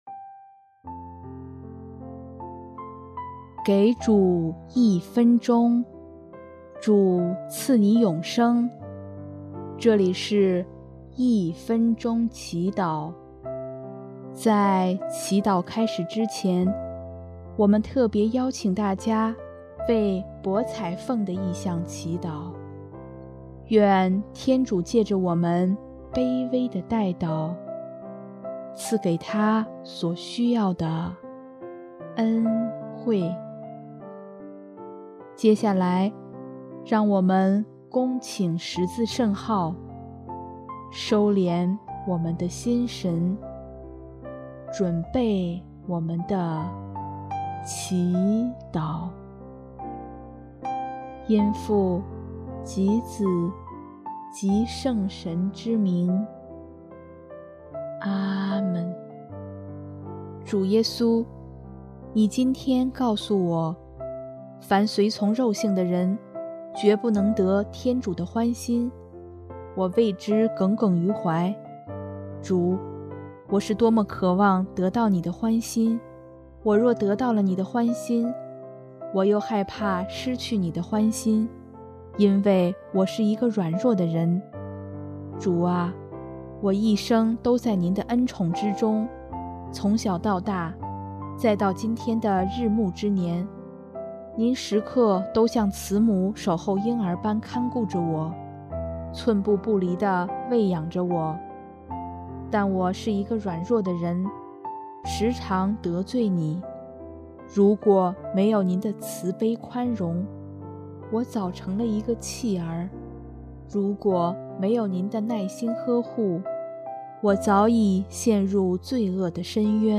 音乐 ：第四届华语圣歌大赛参赛歌曲《奉献付出》